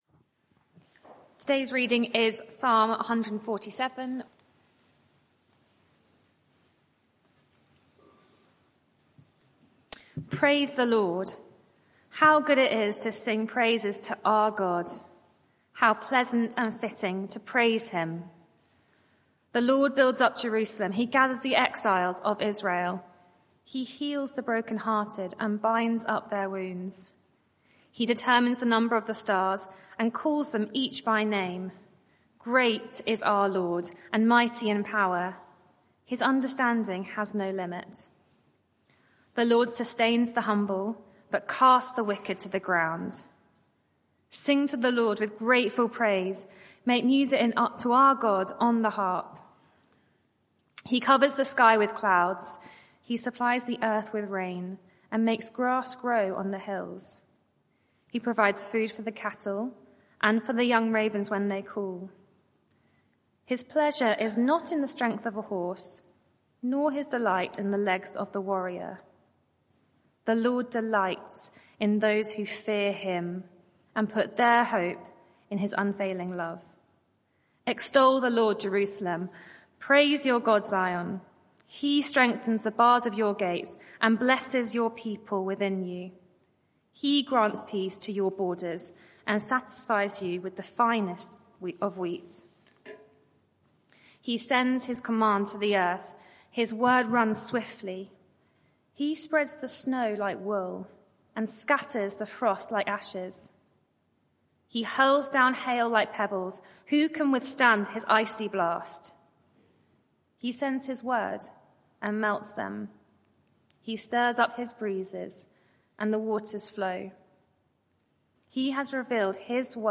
Media for 6:30pm Service on Sun 25th Aug 2024 18:30 Speaker
Theme: When my heart sings Sermon (audio)